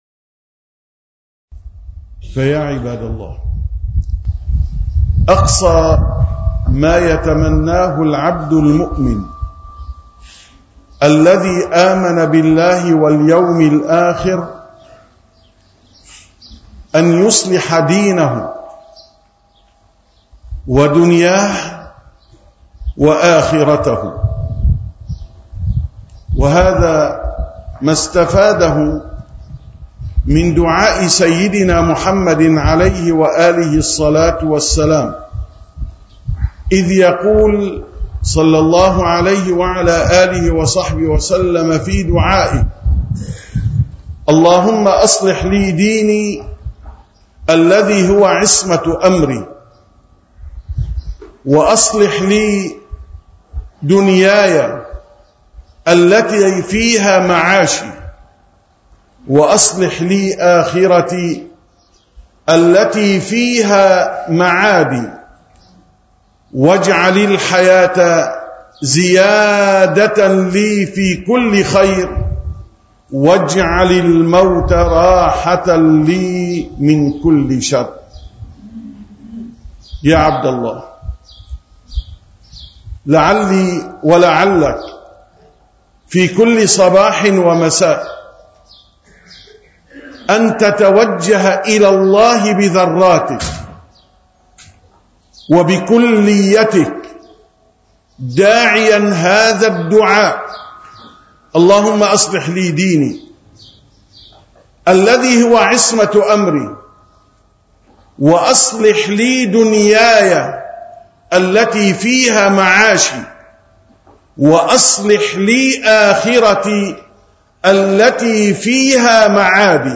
915ـ خطبة الجمعة: مهمة المسلم الإصلاح (2)